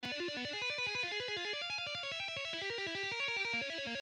guitar tapping exercises
Here is in original speed: